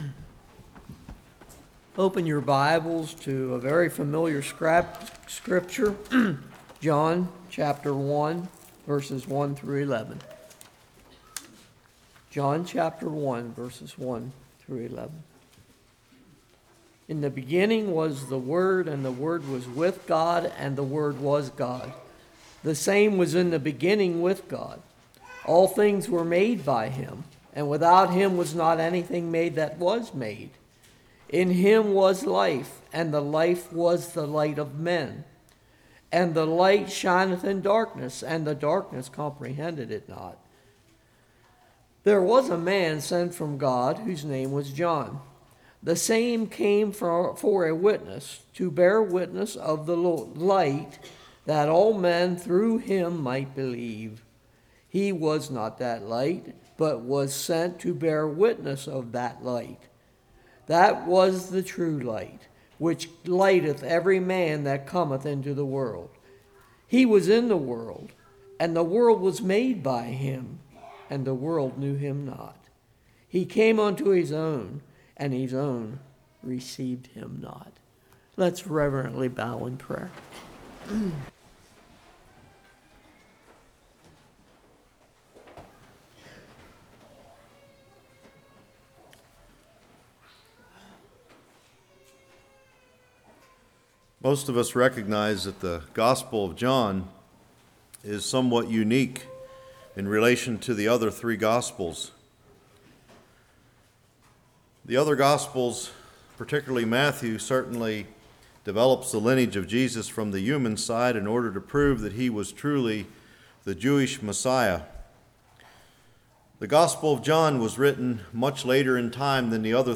Passage: John 1:1-11 Service Type: Morning